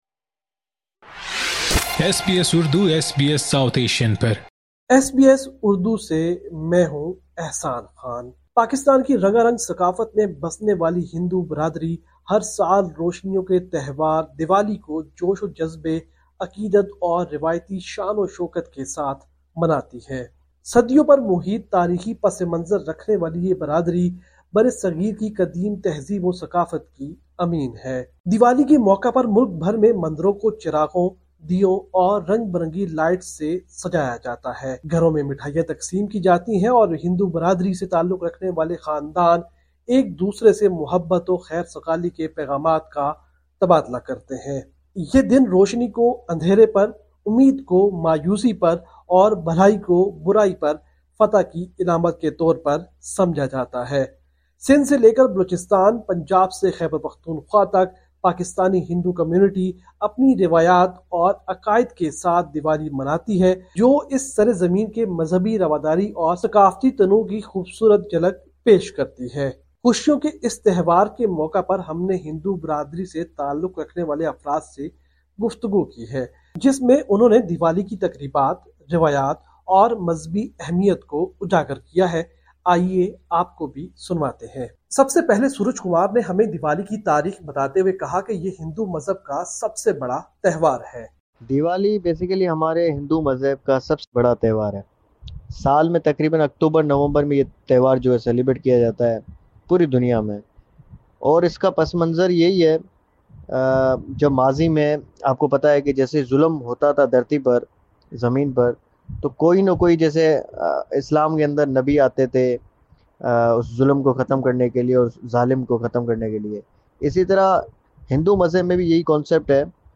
ایس بی ایس اردو کیلئے یہ رپورٹ پاکستان سے